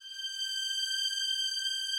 strings_079.wav